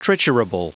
Prononciation du mot triturable en anglais (fichier audio)
Prononciation du mot : triturable